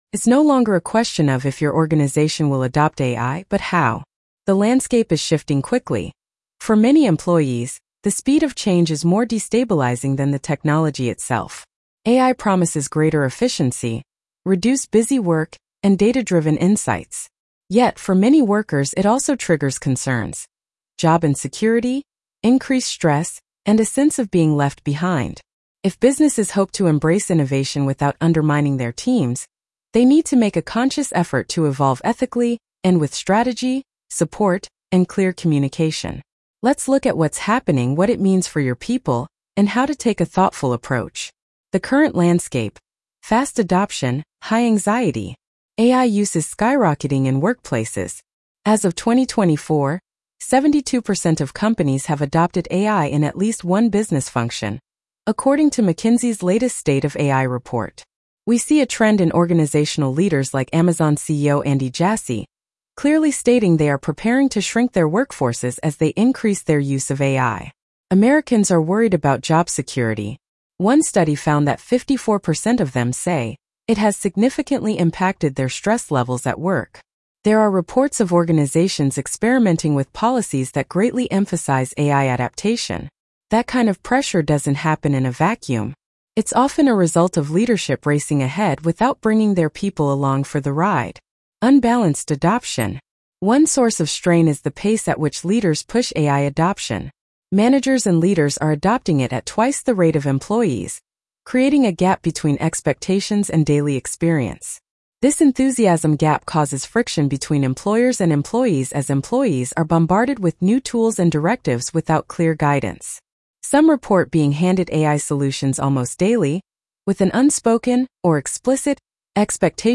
AI, Job Security, and Mental Health How to Evolve Ethically Blog Narration.mp3